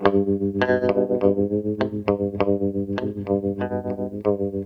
WEIRD02.wav